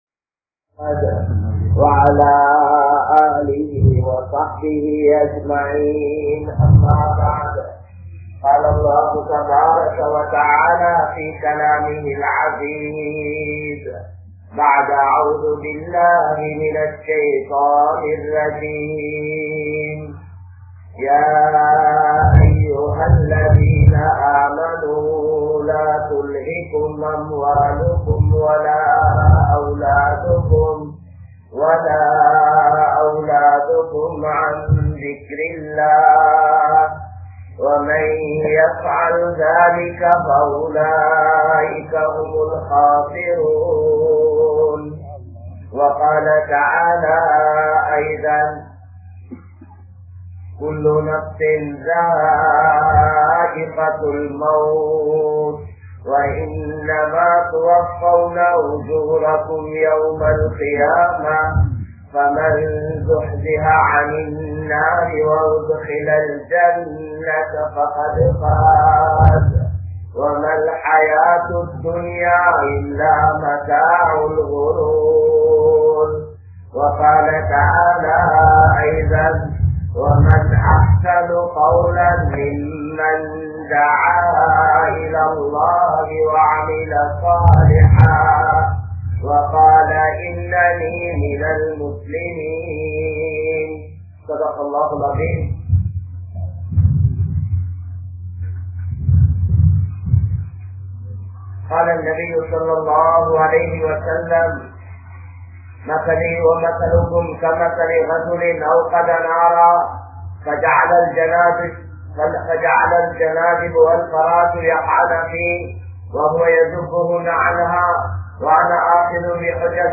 Dhauwaththudaiya Ulaippin Sirappu (தஃவத்துடைய உழைப்பின் சிறப்பு) | Audio Bayans | All Ceylon Muslim Youth Community | Addalaichenai